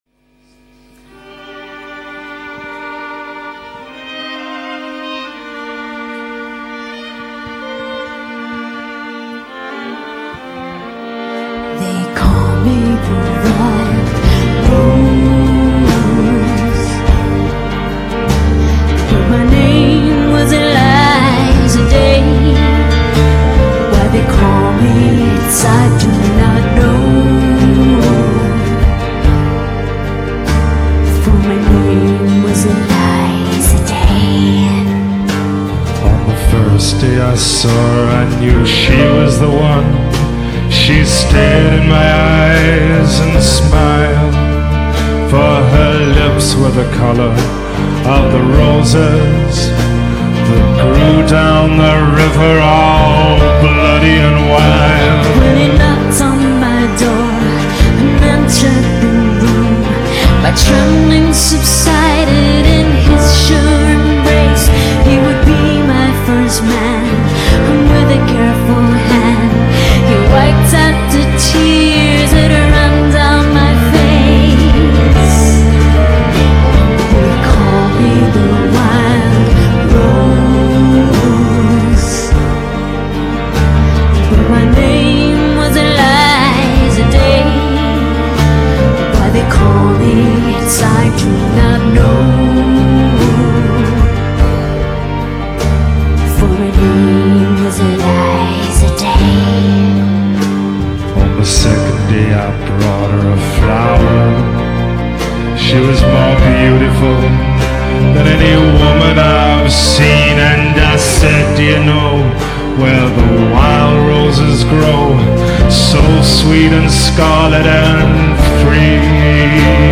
Live :